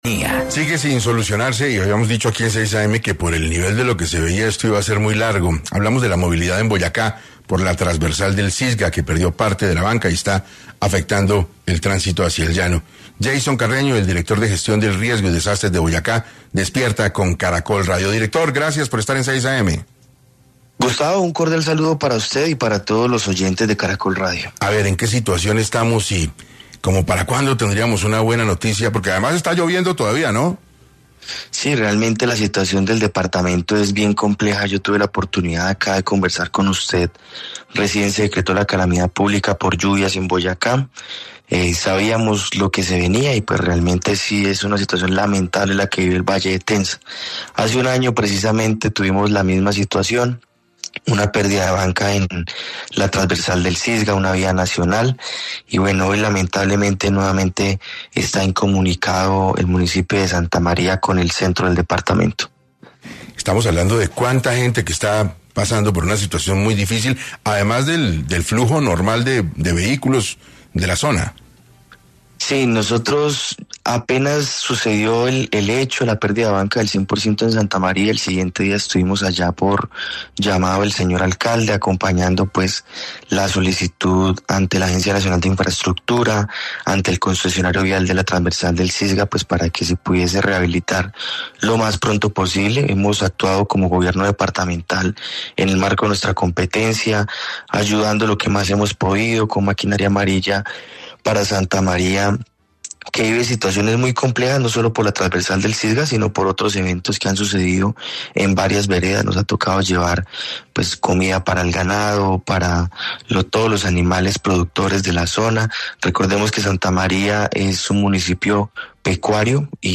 En 6AM de Caracol Radio estuvo Jaisson Carreño, director de Gestión del Riesgo y Desastres de Boyacá, expresó que la situación en el sector sigue siendo complicada y los habitantes están desconectados con el centro del departamento.
Así lo confirmó en entrevista con 6AM de Caracol Radio, Jaisson Carreño, director de Gestión del Riesgo y Desastres de Boyacá, quien advirtió que los esfuerzos continúan para restablecer el paso lo antes posible.